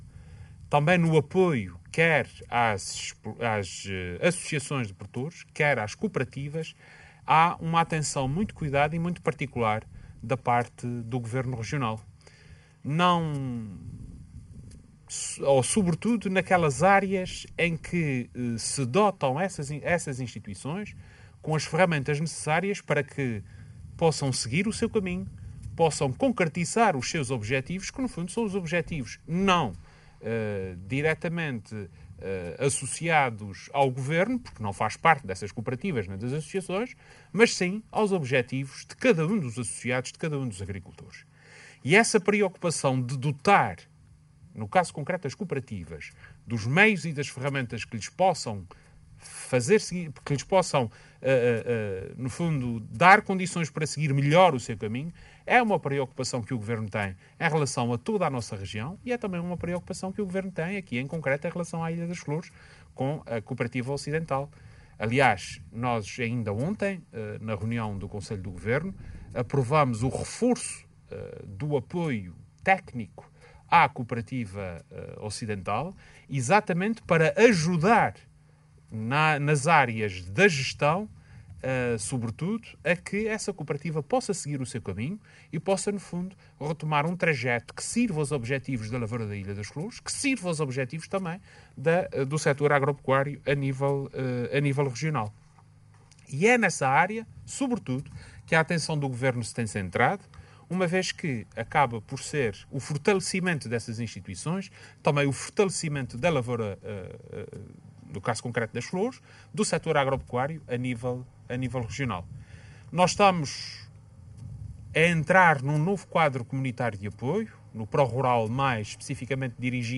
Vasco Cordeiro falava na inauguração, no âmbito da visita estatutária à ilha das Flores, do caminho rural dos Cedros/Tapada Nova, depois de também ter visitado as obras que estão a decorrer de construção do sistema de abastecimento de água à pecuária, investimentos superiores a meio milhão de euros.